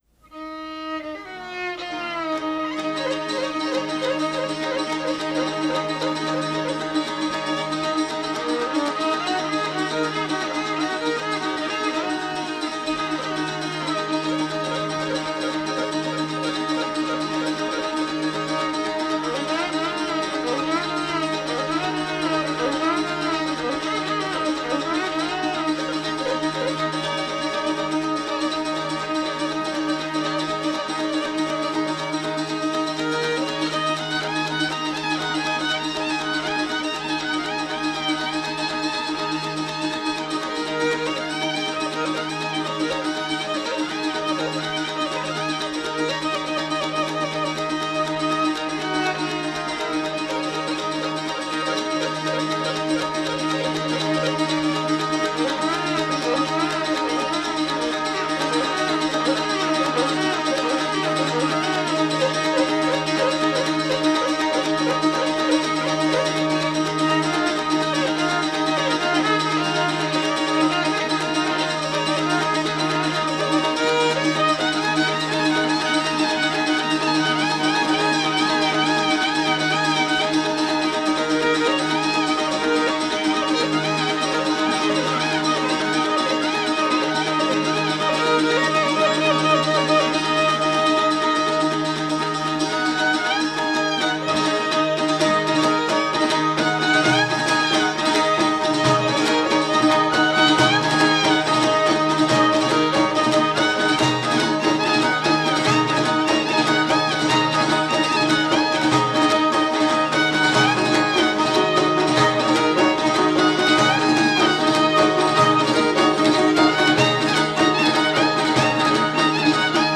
Kaba me dy pjesë: kaba dhe valle. Të gjitha kabatë e qytetit të Delvinës, në pjesën e parë shfaqen si kaba labiko, pra në qendër të tyre qëndron një motiv vajtimor i cili zhvillohet në rritje përmes formulave strikte melodike, glizandove rrëshqitëse në zbritje dhe kadencimeve të septimës minore në ngjitje. Pjesa e dytë ritmike përshfaqet si valle e gëzuar.
violinë
10.-E-qara-me-violine_-sazet-e-Delvines.mp3